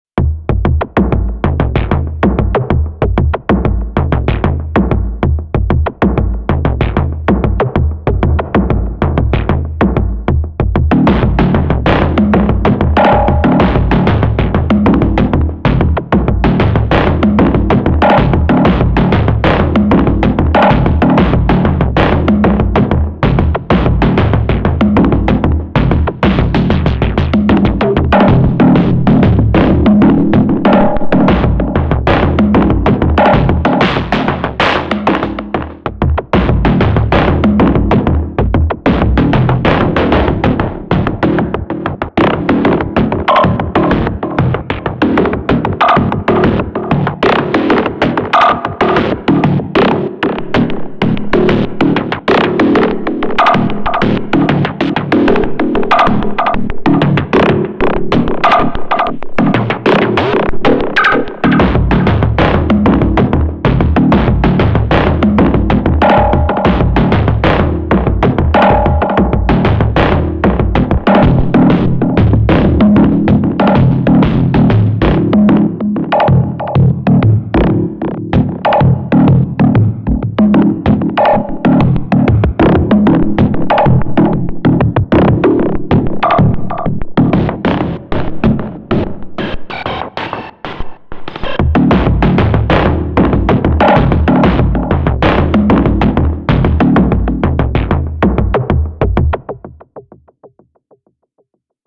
Another (quite different) one with just 2 tracks, the FX track and a bunch of p-locks & control all.